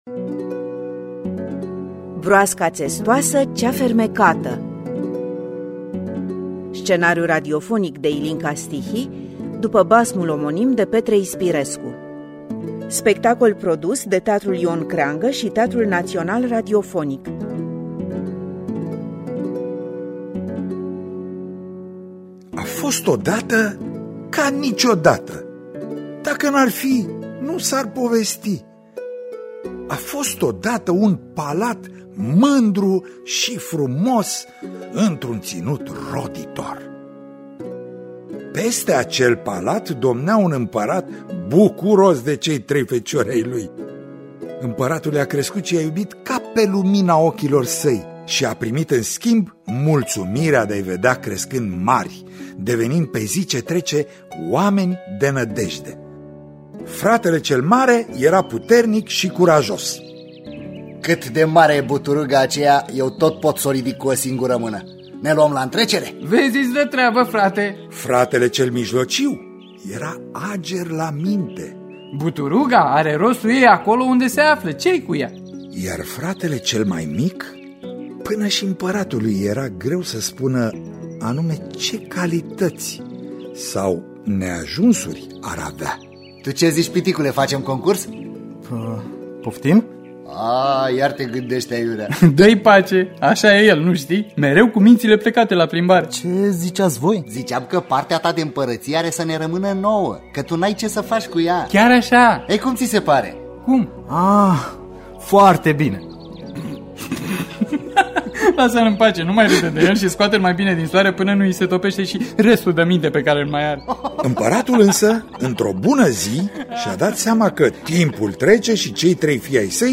Adaptarea radiofonică și regia artistică